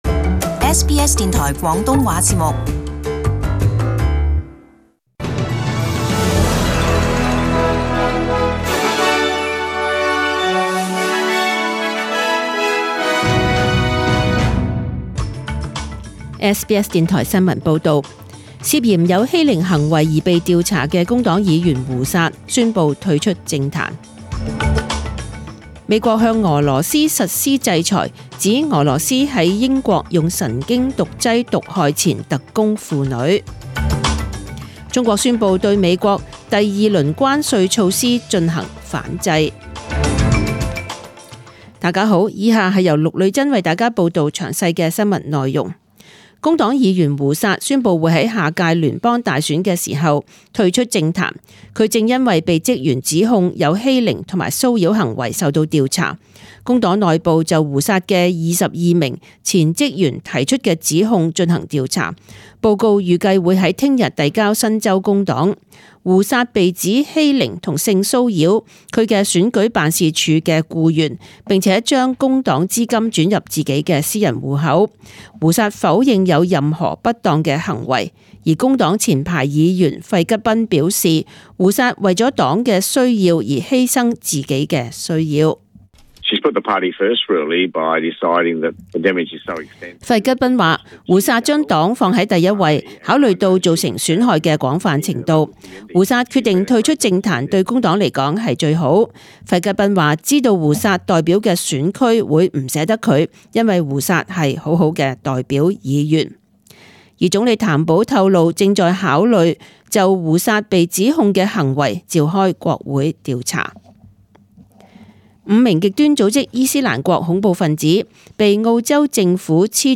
SBS中文新闻 （八月九日）
请收听本台为大家准备的详尽早晨新闻。